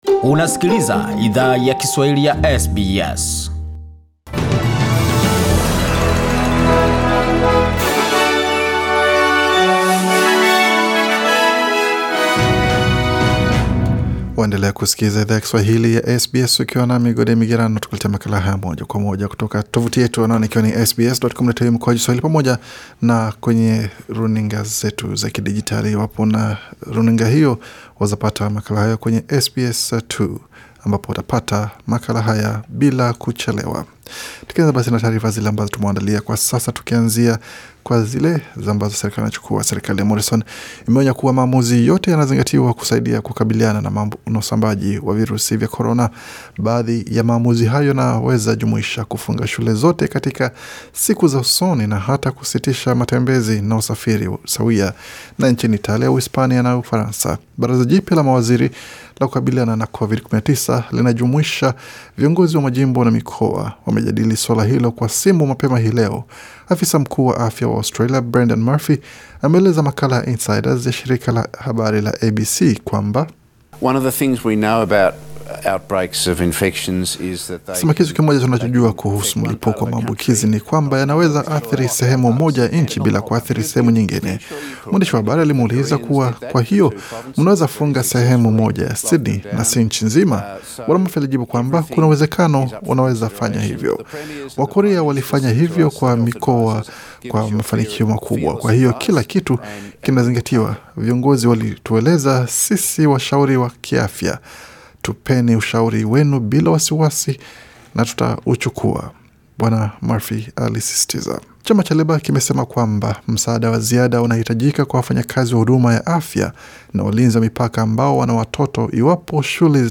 Taarifa za habari: Shule zafungwa Kenya na Rwanda kukabiliana na coronavirus